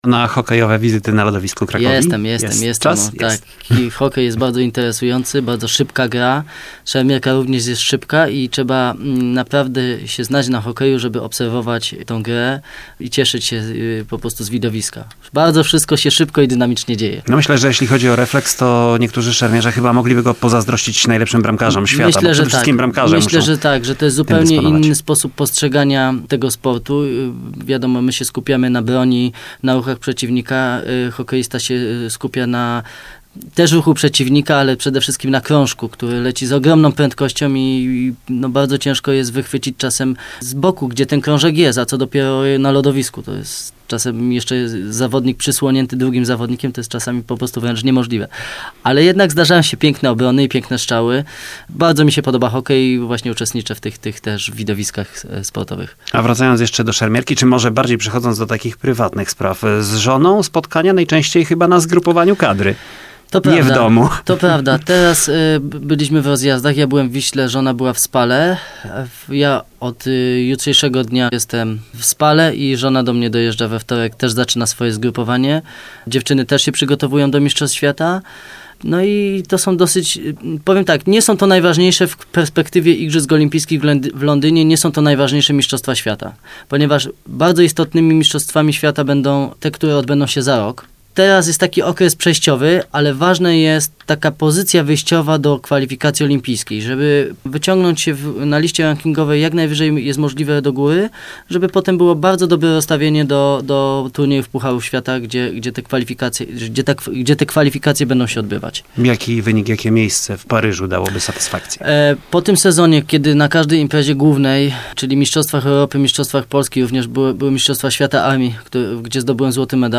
Wywiad dla Radia Kraków